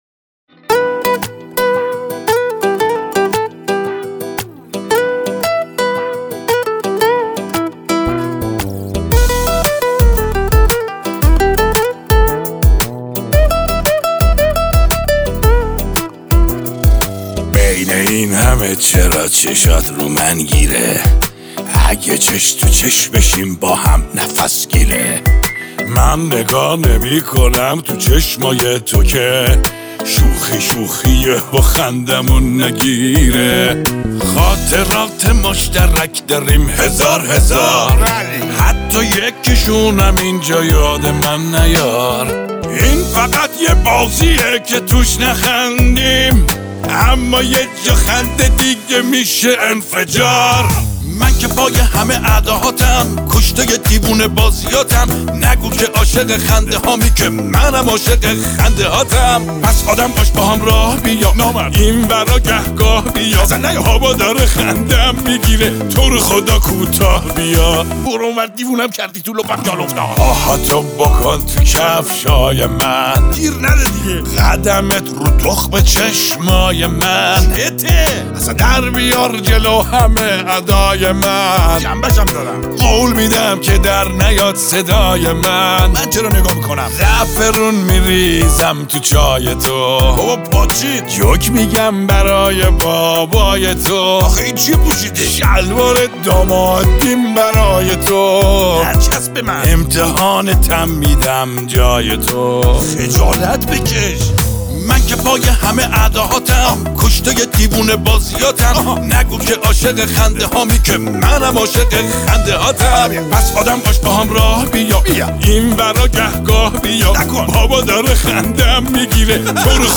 شاد
عاشقانه و غمگین